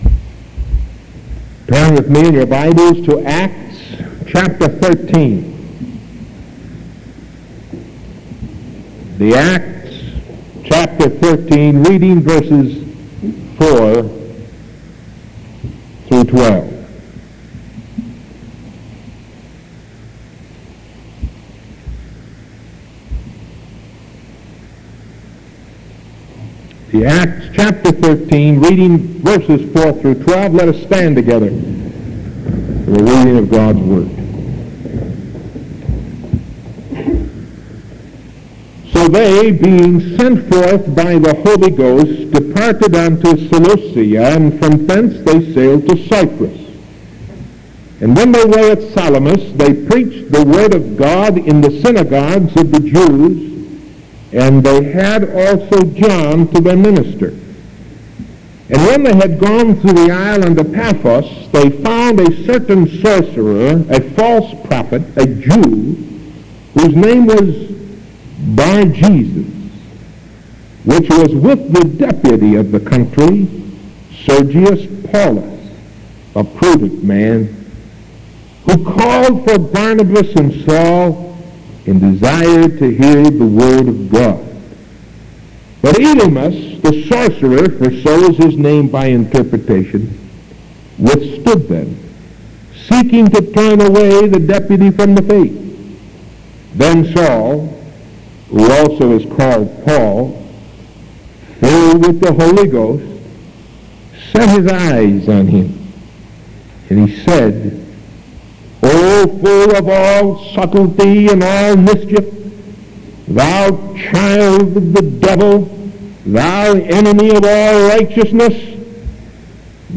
Sermon August 26th 1973 AM